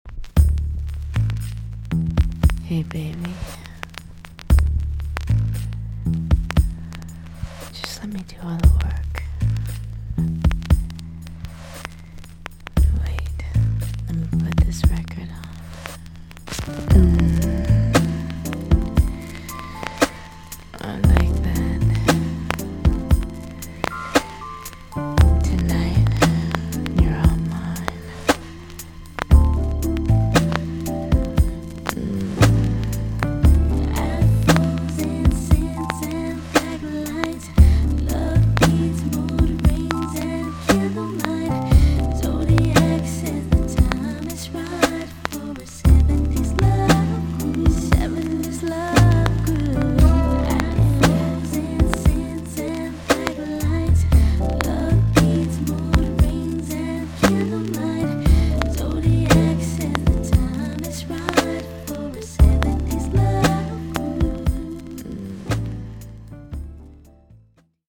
VG+~VG ok 軽いチリノイズが入ります。